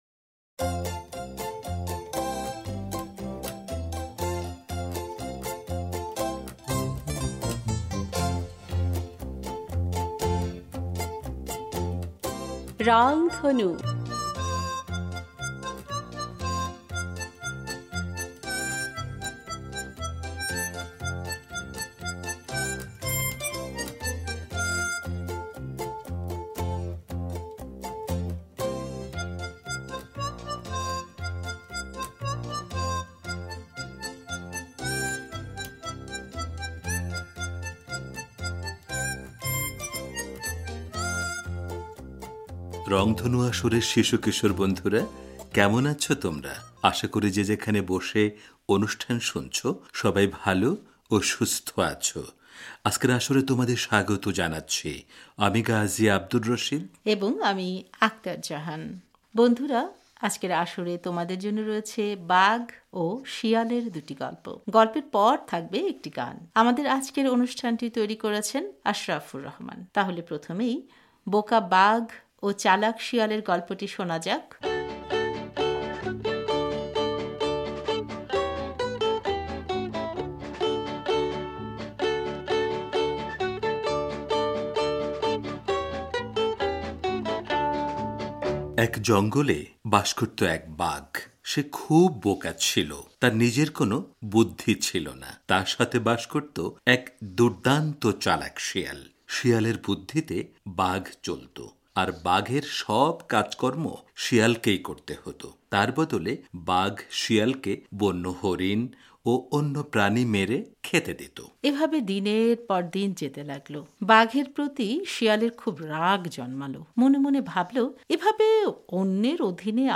বন্ধুরা, আজকের আসরে তোমাদের জন্য রয়েছে বাঘ ও শিয়ালের দুটি গল্প। গল্পের পর থাকবে একটি গান।